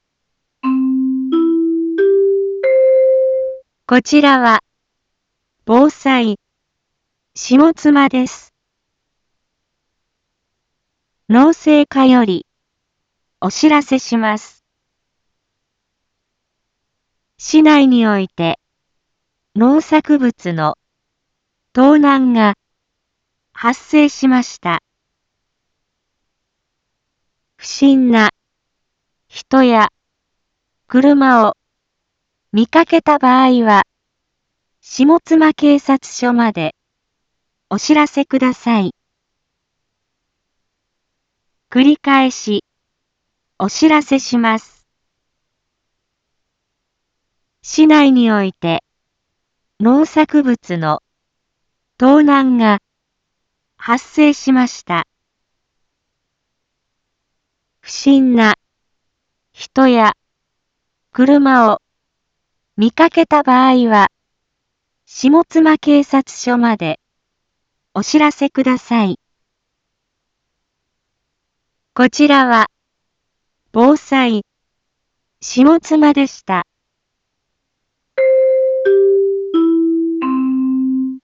一般放送情報
Back Home 一般放送情報 音声放送 再生 一般放送情報 登録日時：2022-09-22 12:31:25 タイトル：農作物の盗難被害について インフォメーション：こちらは、防災、下妻です。